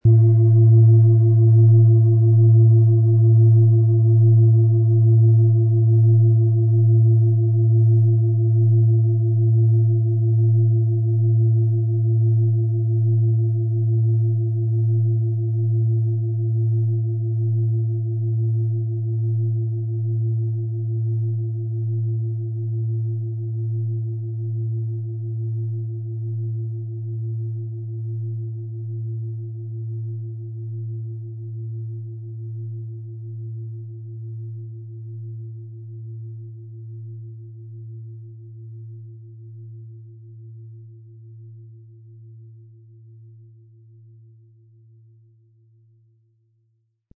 Planetenton 1 Planetenton 2
• Mittlerer Ton: Chiron
• Höchster Ton: Chiron
Im Sound-Player - Jetzt reinhören können Sie den Original-Ton genau dieser Schale anhören.
PlanetentöneThetawelle & Chiron
MaterialBronze